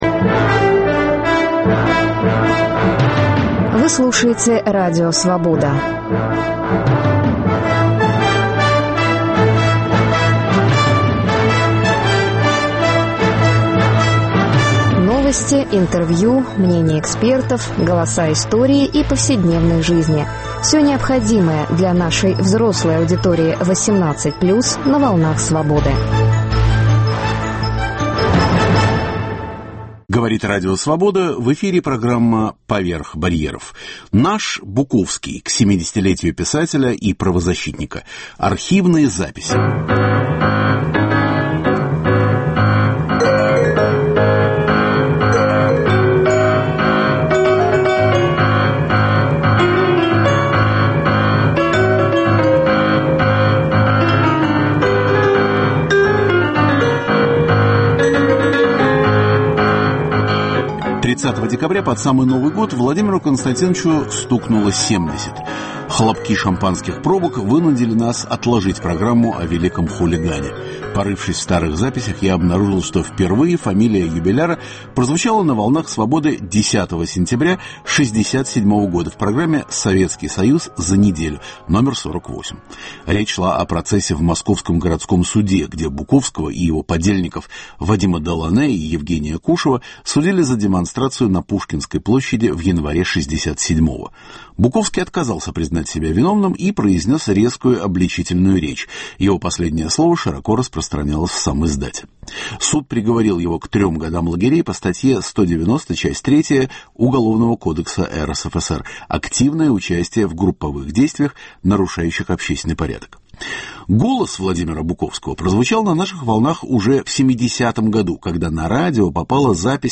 Каждый выпуск программы сопровождают новые музыкальные записи.